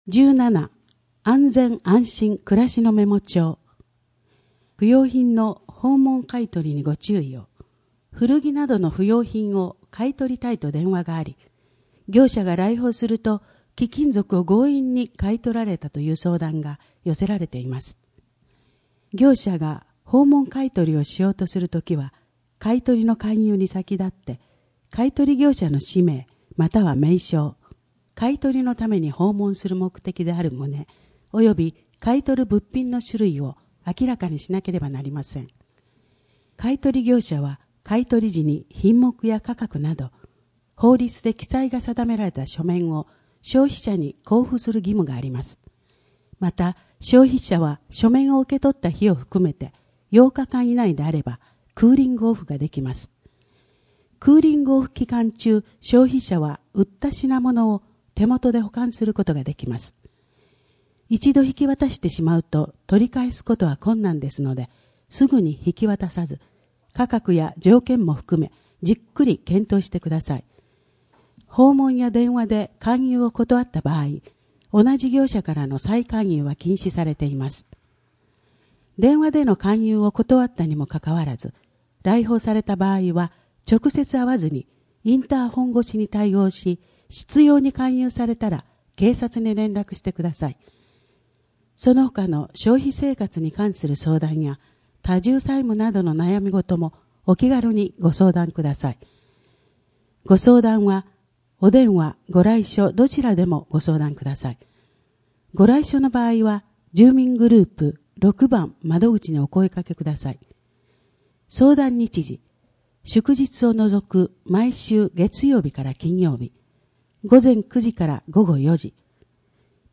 声の「広報はりま」3月号
声の「広報はりま」はボランティアグループ「のぎく」のご協力により作成されています。